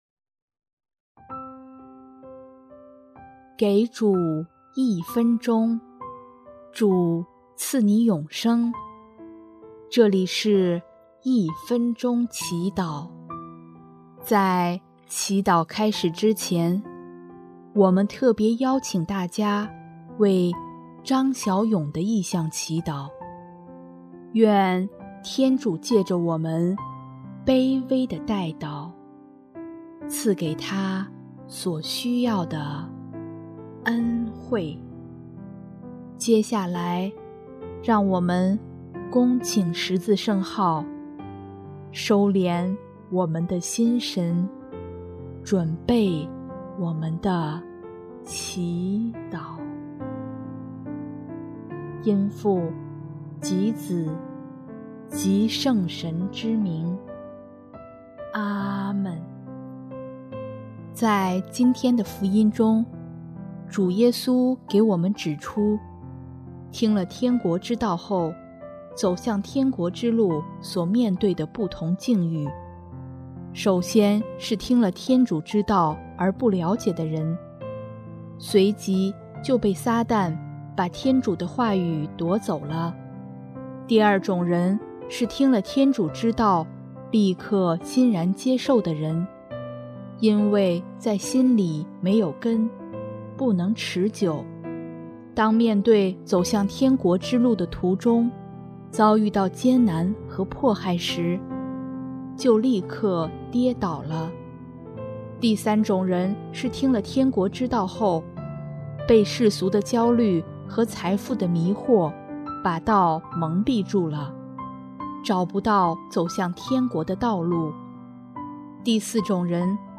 【一分钟祈祷】|7月26日 听天国之道，走天国之路